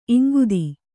♪ iŋgudi